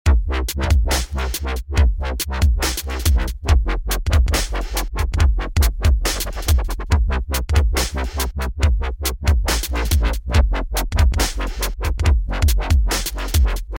This is basically a bass with a filter being modulated in a rhythmic sync with the tempo.
Start by selecting the pencil tool or just hit ‘W’ and draw in these notes: C2, F#1, C2, A#1, F#1.
Lastly, hit record and modulate it any way you like.